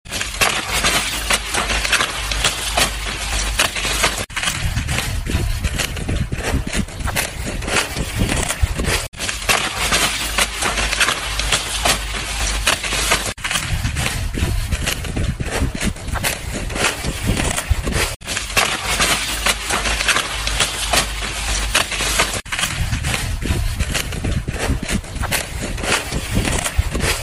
Tiếng dùng dao, lưỡi liềm, tay… thu hoạch rau củ quả sột soạt
Tiếng Thu hoạch, Nhổ rau củ quả bằng tay… Tiếng dùng kéo, móng tay Cắt rau, Ngắt hái rau củ quả cật cật…
Thể loại: Tiếng động
Âm thanh kết hợp giữa tiếng lá cây xào xạc, tiếng dao chạm thân cây, và âm thanh chạm tay vào rau tạo nên một bản audio hiệu ứng chân thực, giúp bạn dễ dàng chèn vào video dựng cảnh thu hoạch, làm phim phóng sự, hay video ASMR.
tieng-dung-dao-luoi-liem-tay-thu-hoach-rau-cu-qua-sot-soat-www_tiengdong_com.mp3